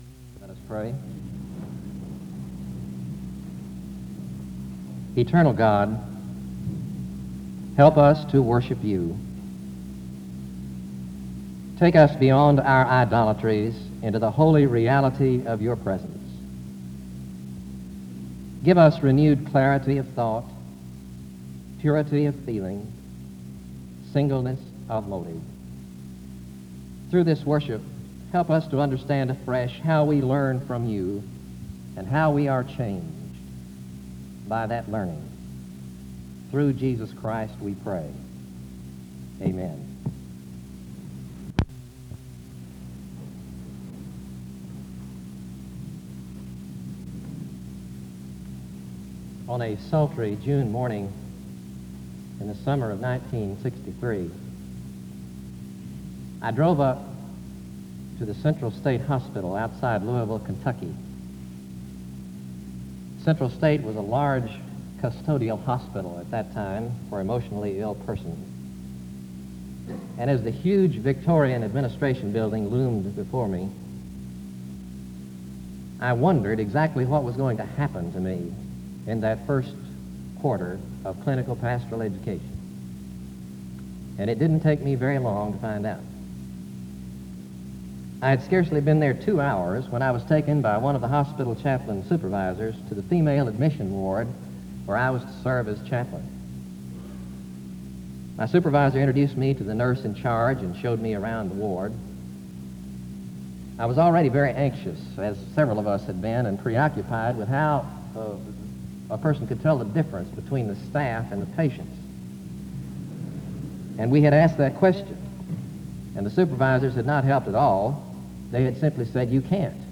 The service starts with a word of prayer from 0:00-0:39.
SEBTS Chapel and Special Event Recordings